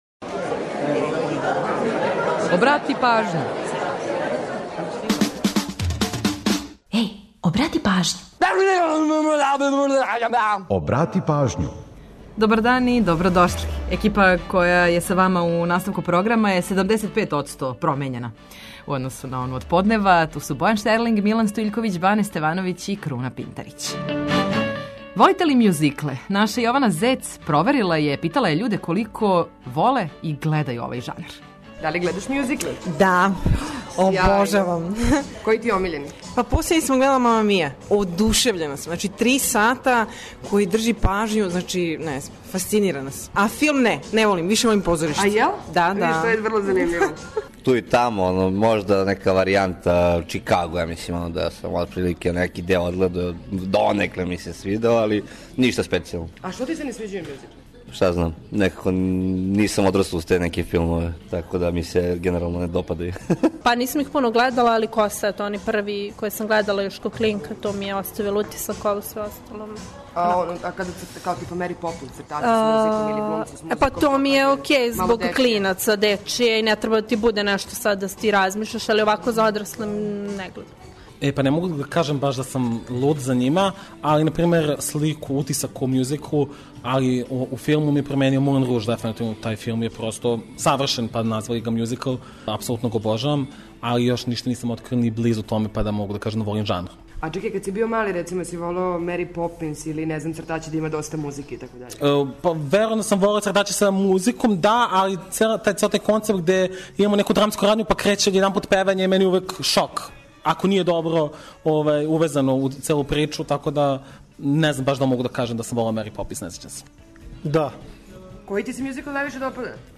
Колико је у Србији мјузикл омиљен и популаран? Тим поводом анкетирали смо пролазнике, а очекујемо и ваше коментаре.